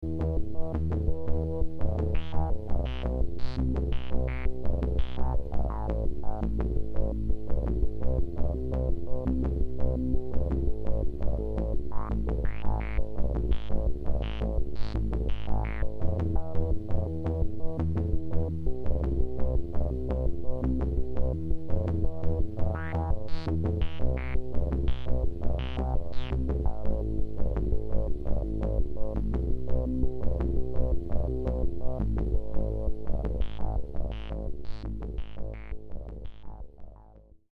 this is just a shoprt sample how i use the baby10 to control the klee.
the klee controls two voices (EFM VCOs, ssm0244, LPG). the CVs for pitch are fed to the dopefer quantizer. the base frequency of the quantizer is controlled by the baby10. the master LFO clocks the klee and a divider, which ckocks the baby10:
Very Happy I love the sound of those filters getting mixed around.
aargh. forgot to mention the last piece in chain: the LM1036 audio processor. the panning was controlled by one of the klees gate outputs (can't remember which one, patch is overwritten).